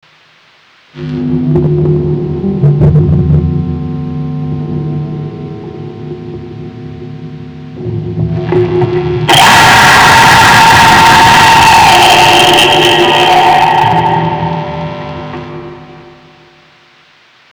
hum, on a essayer, ça a donner un truc, lui avec ça guitare il a un son, moi avec la basse un son aussi, avec une reverbe, beaucoup de tonaliter et un micro simple ... ( j'avais pas oser le dire avant )
des interets a faire ça ? tres peu, mais avec un peu d'imagination ça peut faire un truc ... mais on a pas vraiment chanter ... ça ressemble a une mouettes qui agonise ... mais c'est marrant
ici a travers la guitare
Scream_guitar.mp3